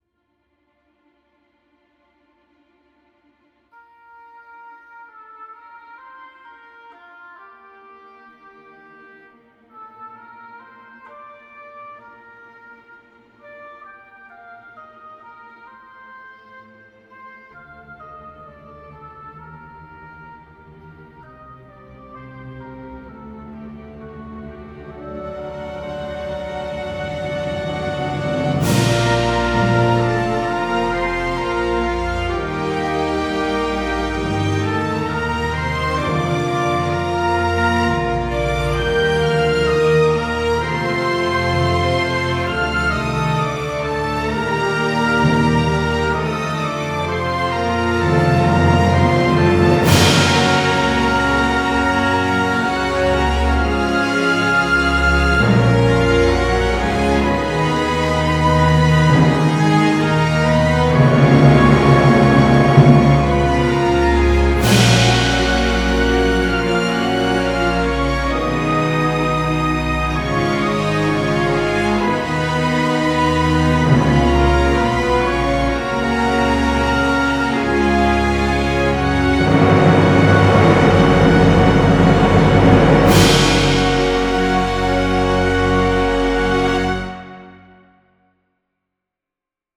Respiro sinfonico per Orchestra
* PICCOLO, 2 FLUTE, 2 OBOE, 2 CLARINET IN Bb, 2 BASSOON
* 2 HORN IN F, 2 TRUMPET IN Bb
* TIMPANI CYMBALS
* VIOLIN I VIOLIN II VIOLA VIOLONCELLO CONTRABASS